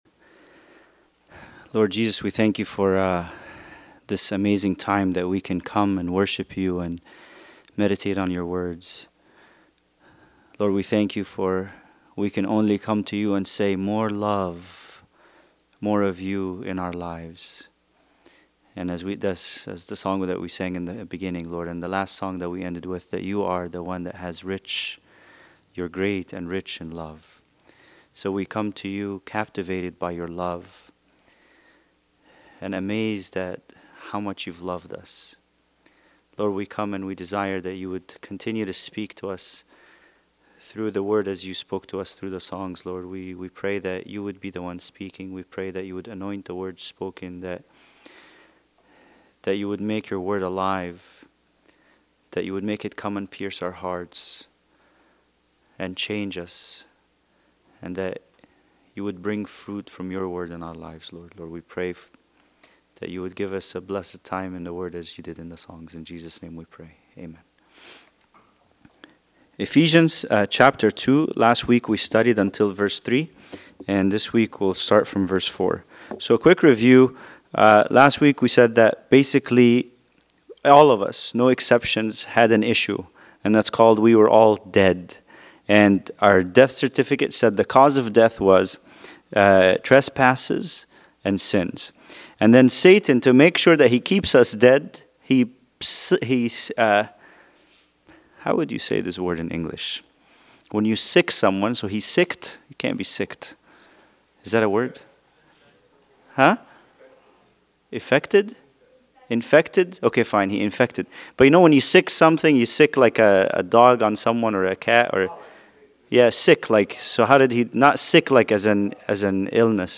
Bible Study: Ephesians 2:4-6